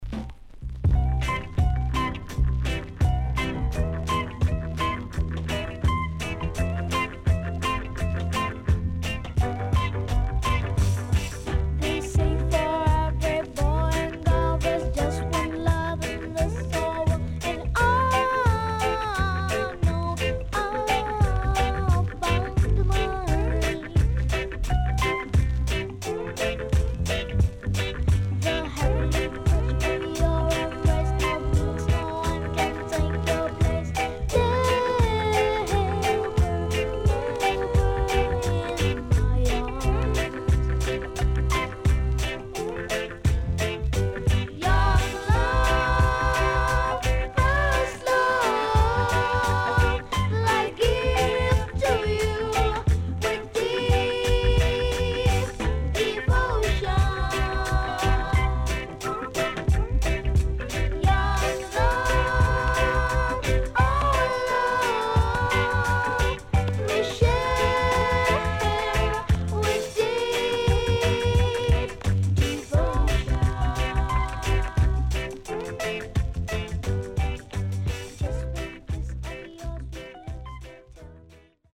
SIDE A:プレス起因により少しノイズ入ります。